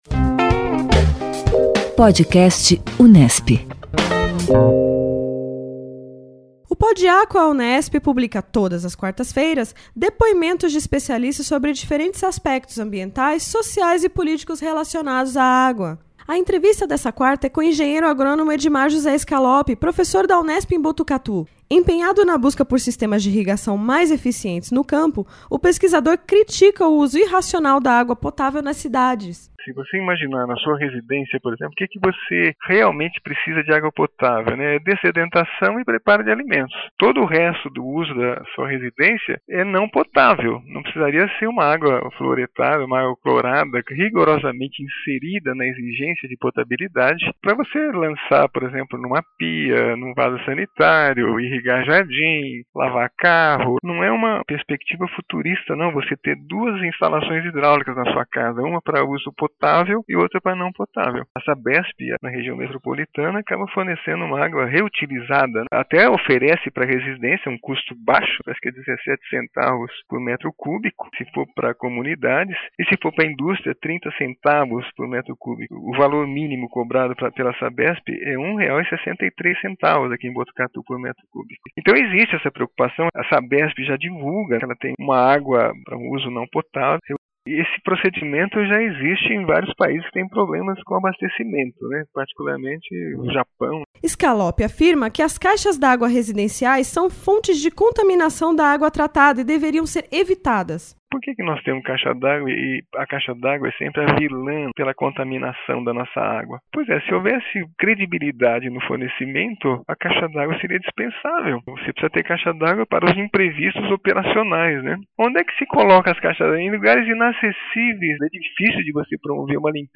O PodAcqua traz trechos de entrevistas com especialistas da Unesp e de outras instituições, nas mais diferentes áreas do conhecimento, com atenção especialmente voltada à gestão responsável dos recursos hídricos.